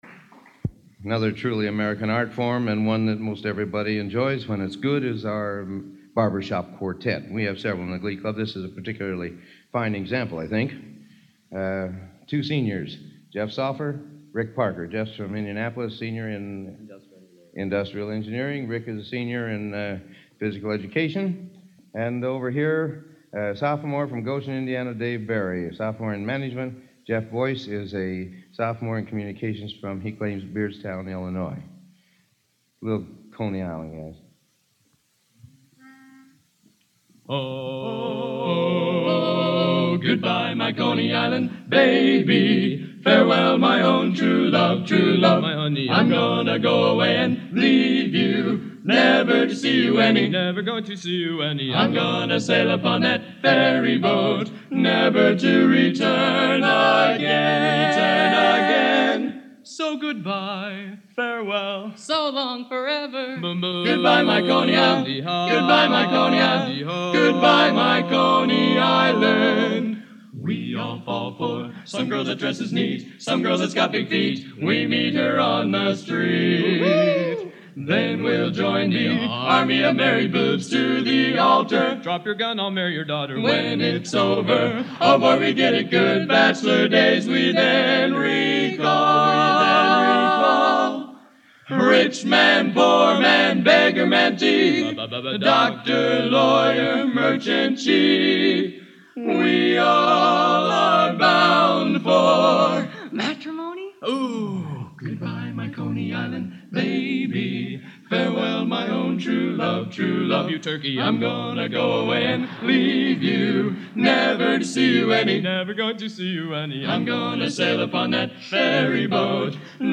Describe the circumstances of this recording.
Genre: A Cappella Barbershop | Type: End of Season